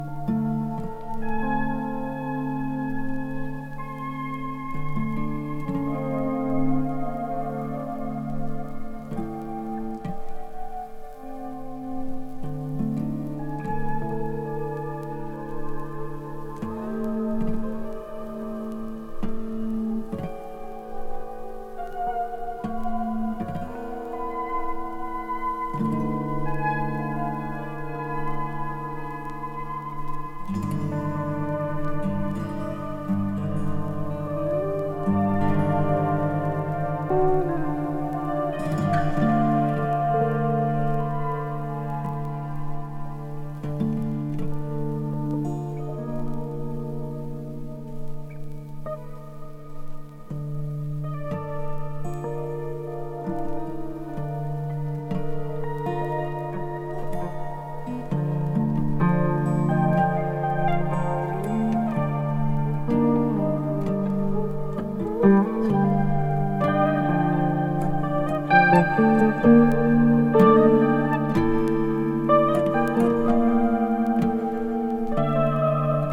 アコースティックギター、エレクトリックギター、マンドリンを1人で演奏し構成された作品。